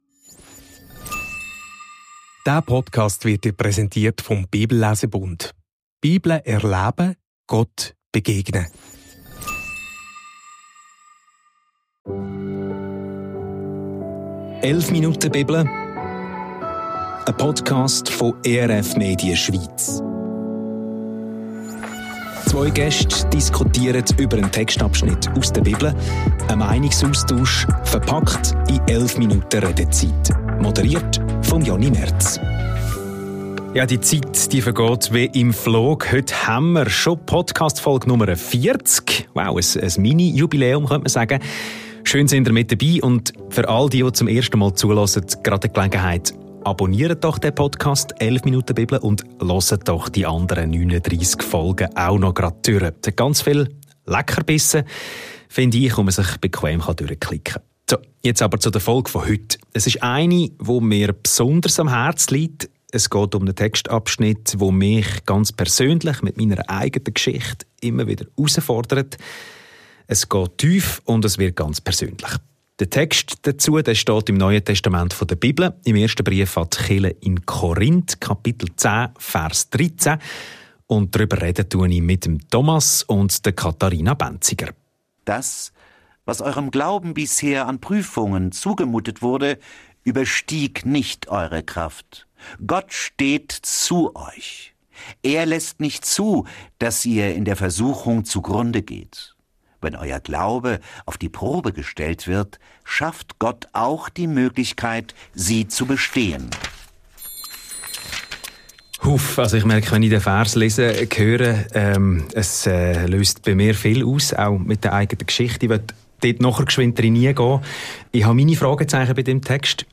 Ehrlich, ungefiltert und persönlich.